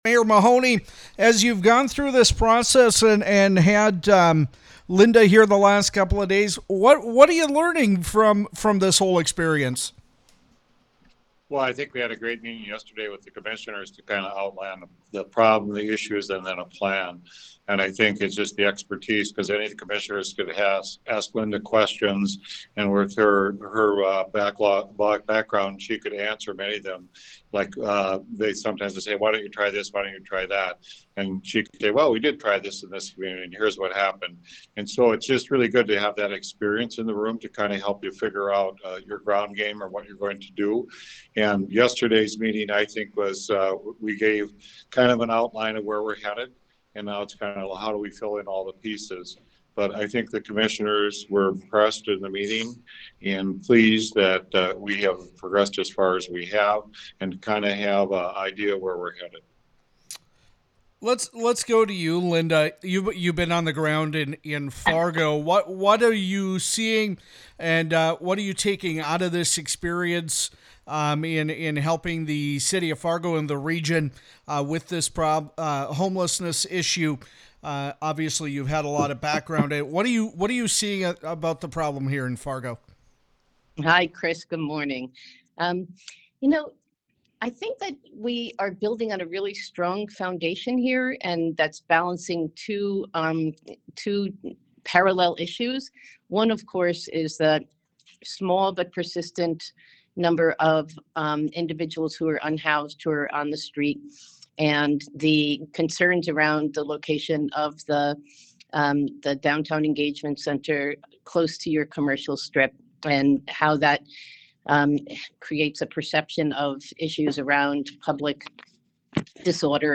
Interview with Linda Gibbs